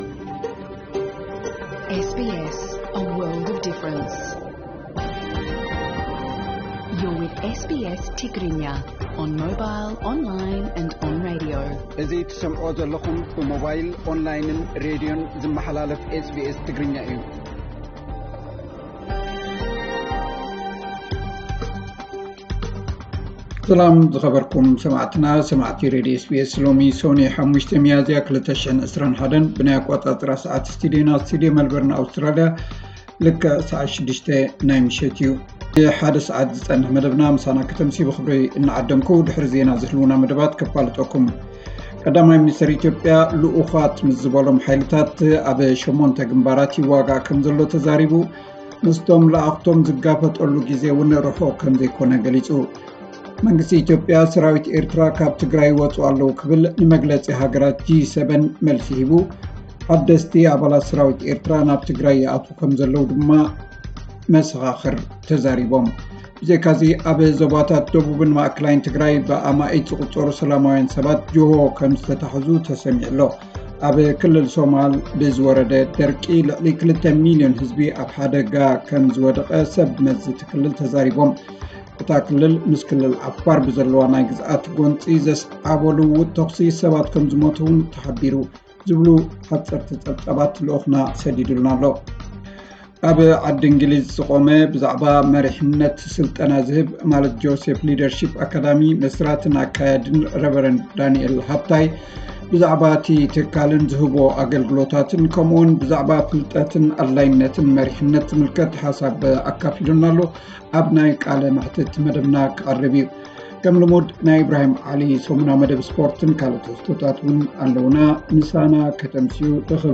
ዕለታዊ ዜና 5 ሚያዚያ 2021 SBS ትግርኛ